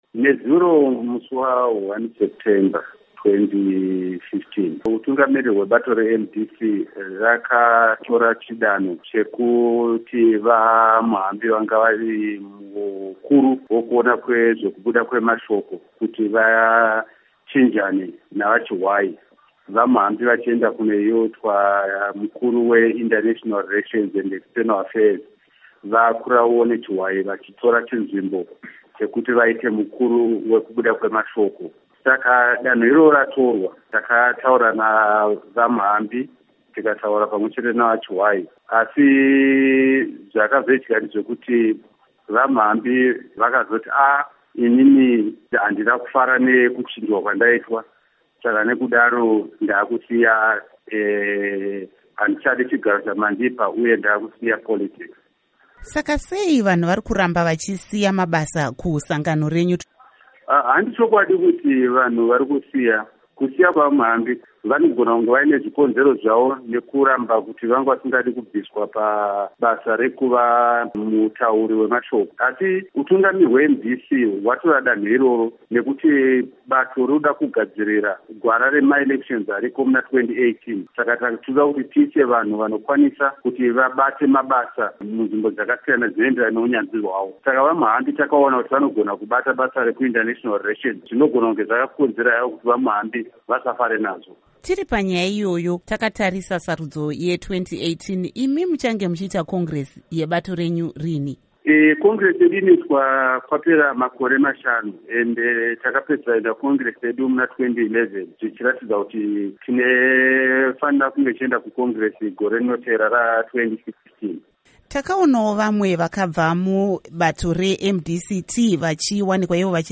Hurukuro NaVa Edwin Mushoriwa